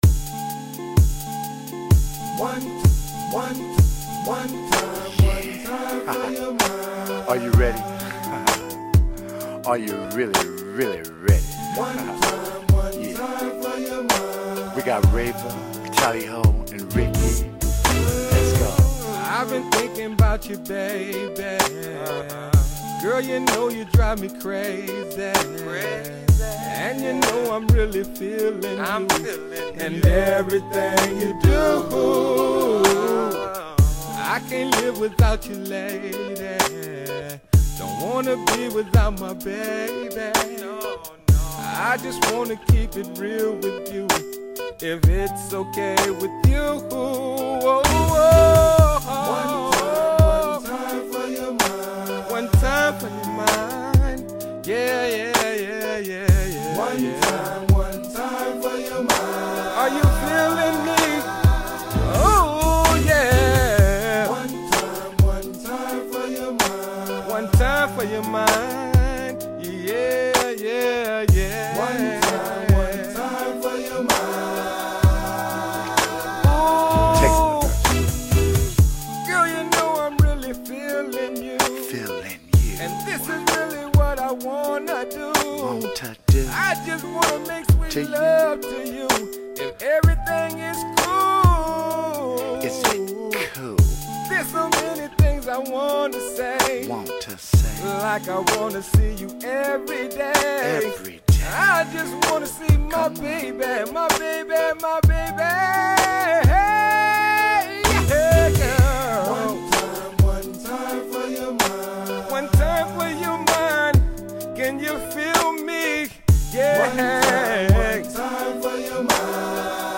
They rocked there hood with beautiful harmomy.